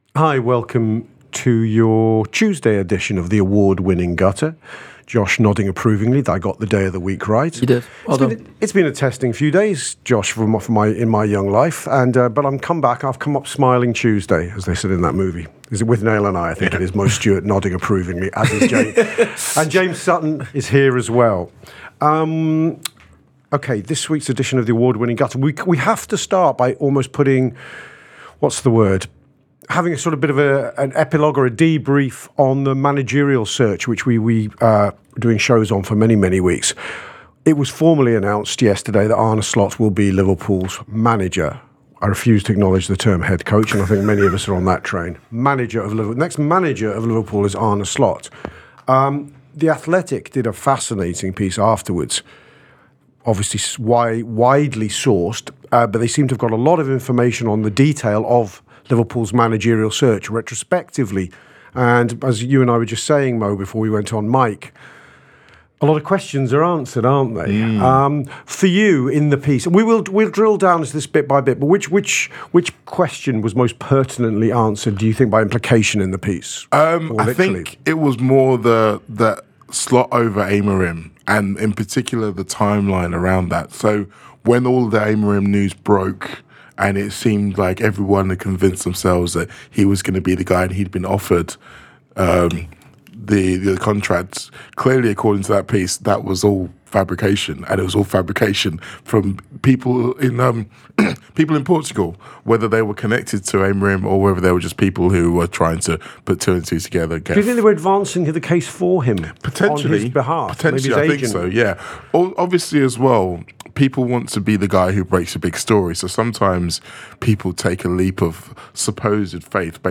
Below is a clip from the show – subscribe for more on Arne Slot’s first transfer to-dos…